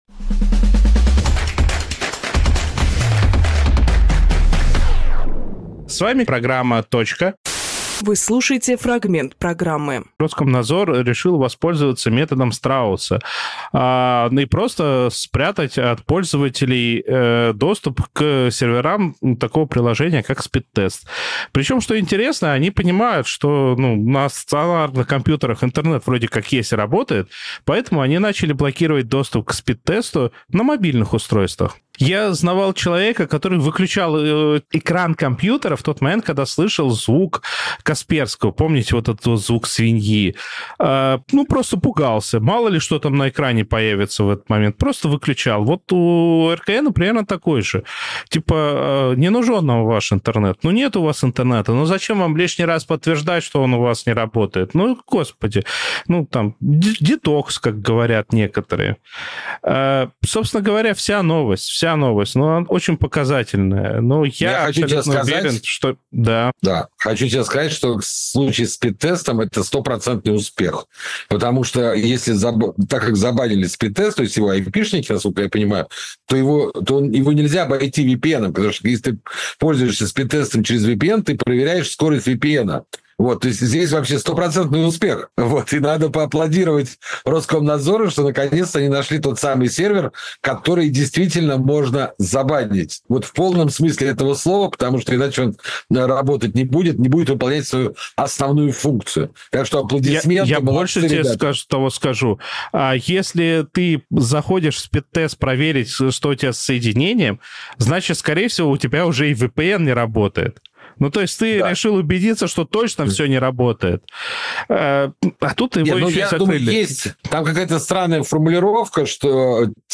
Фрагмент эфира от 03.08.25